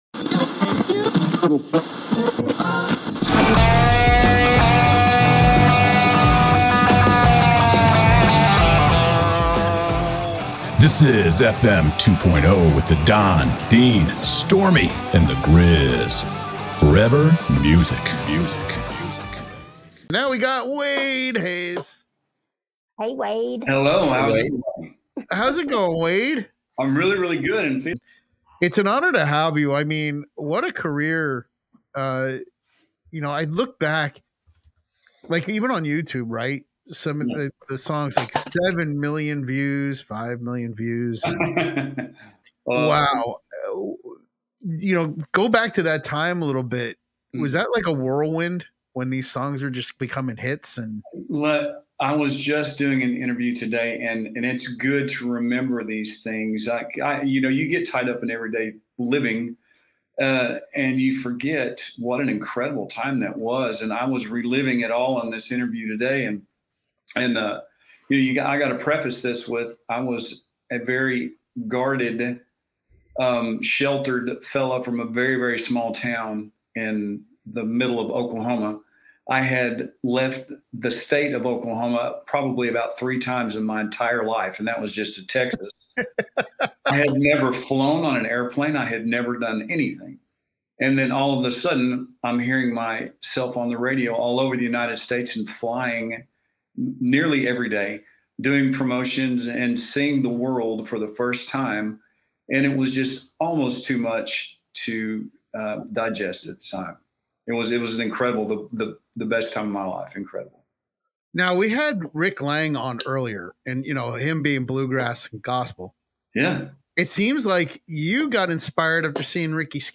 90's Country Chart topper Wade Hayes joined us and we talk about his experiences of becoming an overnight sensation in country music and the feeling of being overwhelmed. We talked about how the 90s sound is making a comeback in modern country.